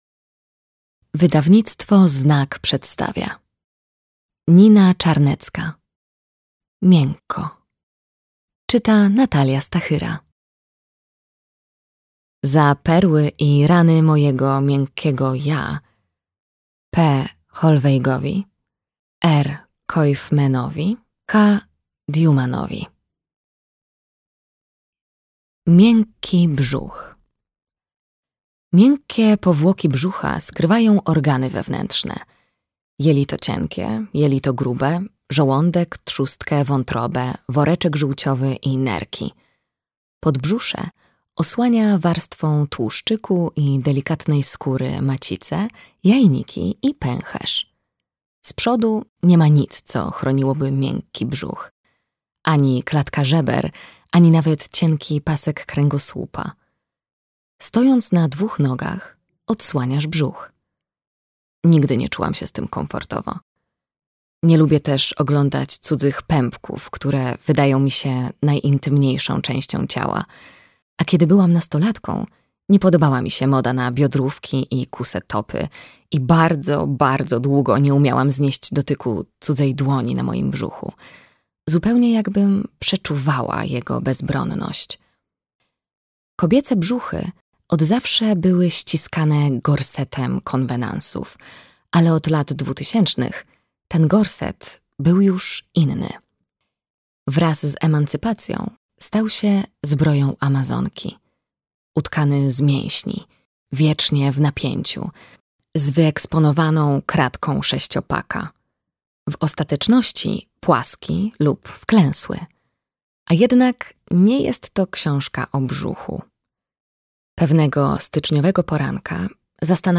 Miękko - Czarnecka Nina - audiobook + książka